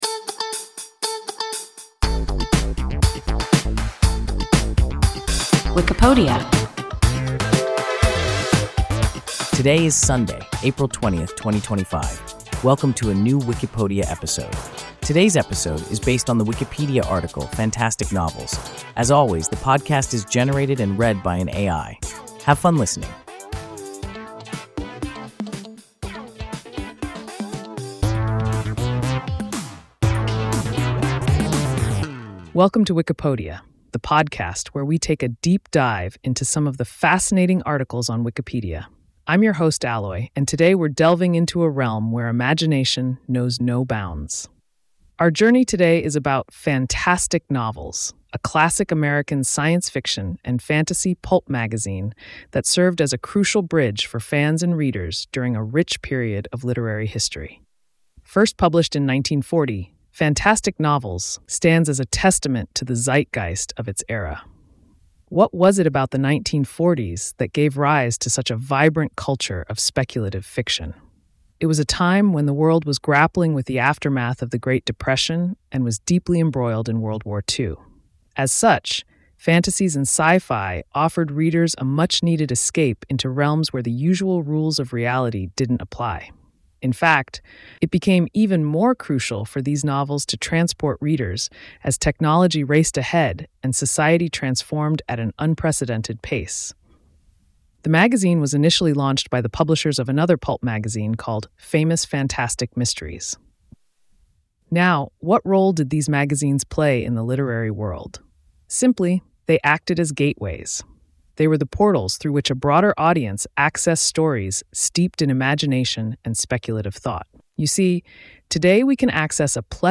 Fantastic Novels – WIKIPODIA – ein KI Podcast